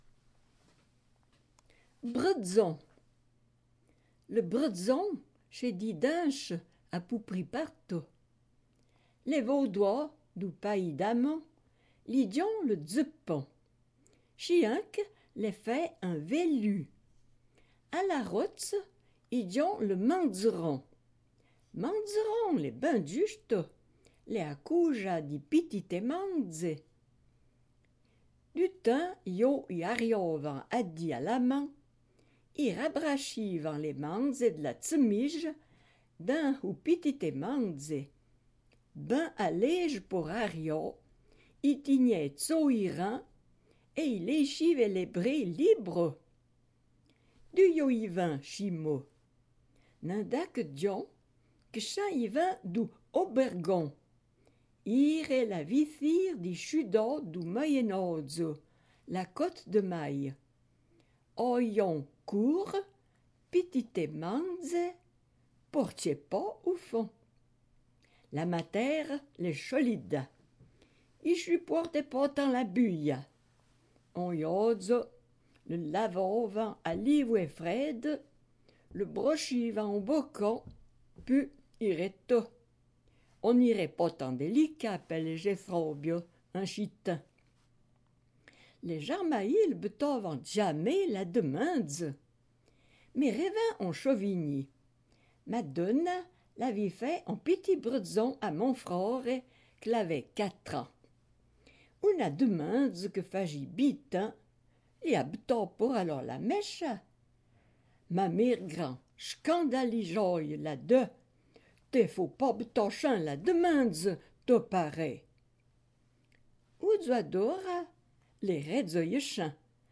En patois